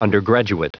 Prononciation du mot undergraduate en anglais (fichier audio)
Prononciation du mot : undergraduate